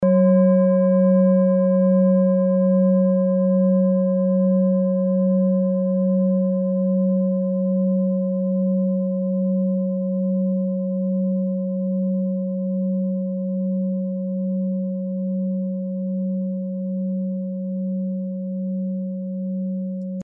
Klangschale TIBET Nr.6
Die 37. Oktave dieser Frequenz liegt bei 241,56 Hz. In unserer Tonleiter liegt dieser Ton nahe beim "H".
klangschale-tibet-6.mp3